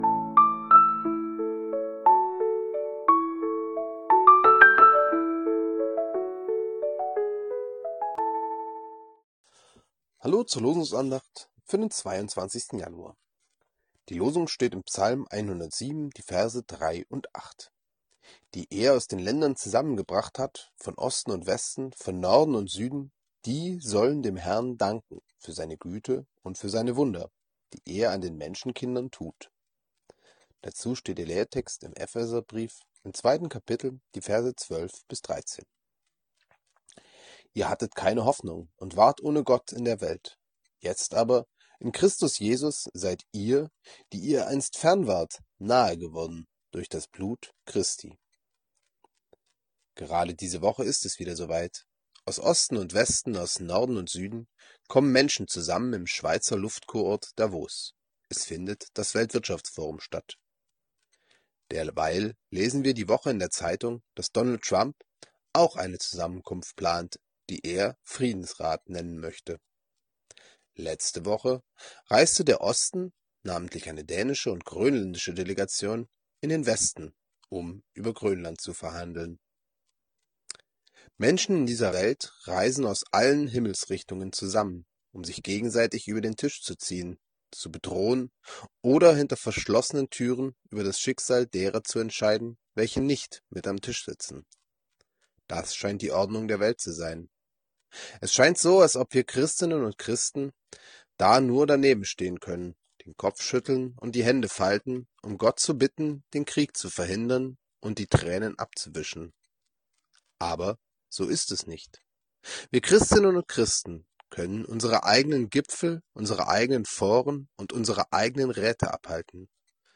Losungsandacht für Donnerstag, 22.01.2026 – Prot.